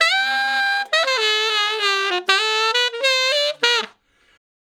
066 Ten Sax Straight (D) 39.wav